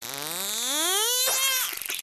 دانلود آهنگ دلفین 11 از افکت صوتی انسان و موجودات زنده
دانلود صدای دلفین 11 از ساعد نیوز با لینک مستقیم و کیفیت بالا
جلوه های صوتی